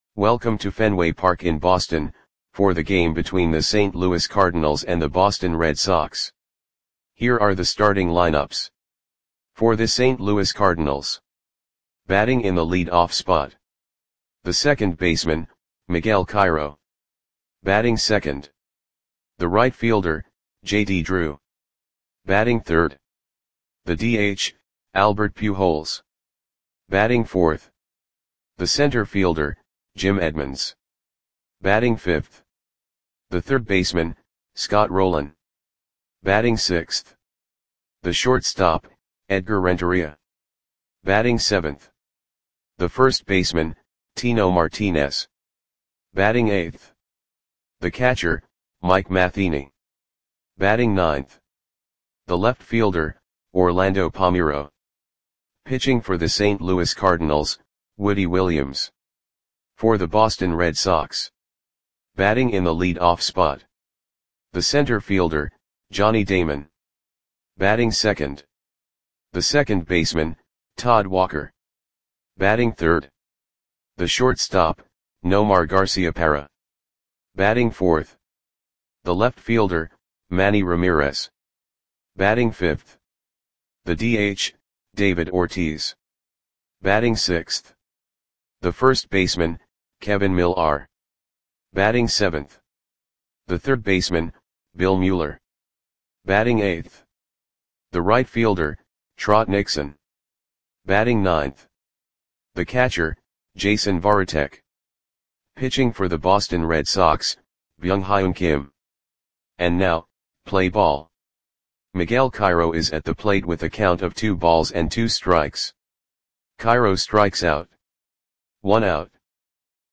Audio Play-by-Play for Boston Red Sox on June 10, 2003
Click the button below to listen to the audio play-by-play.